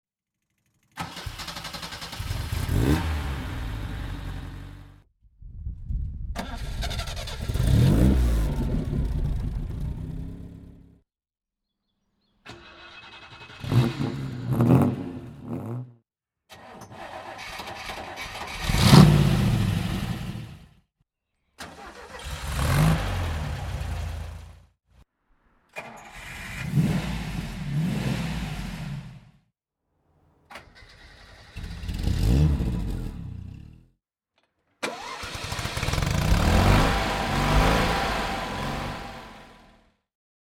The hum of the starter motor
To remind us of the good old days, we have put together a little starter potpourri that you shouldn't miss.
Event concert - different car brands and types
Anlasserkonzert.mp3